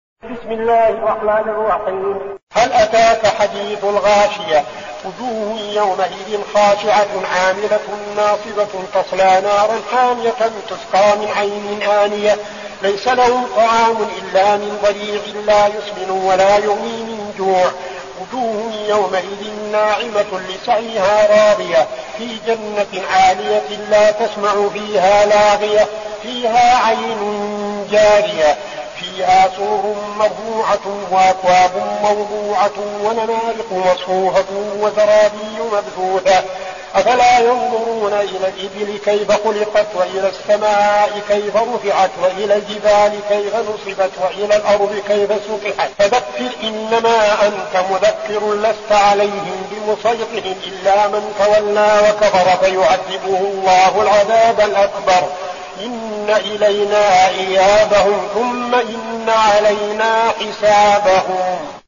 المكان: المسجد النبوي الشيخ: فضيلة الشيخ عبدالعزيز بن صالح فضيلة الشيخ عبدالعزيز بن صالح الغاشية